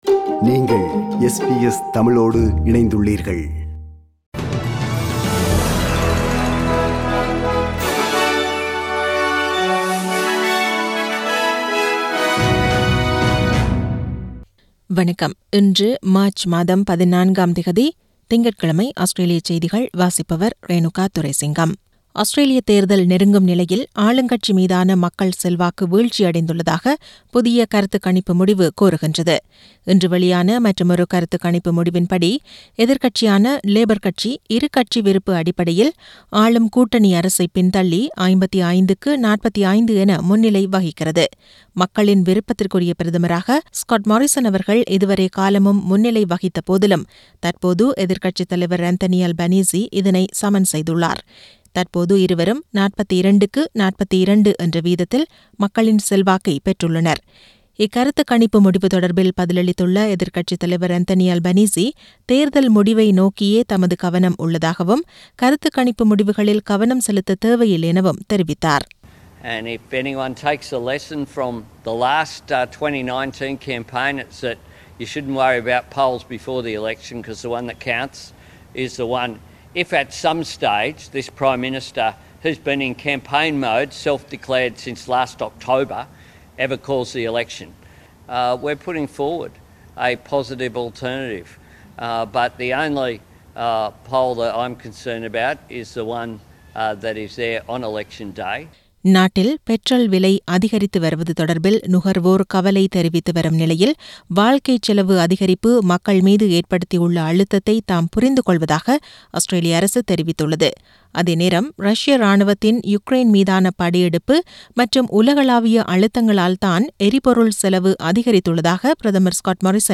Australian news bulletin for Monday 14 Mar 2022.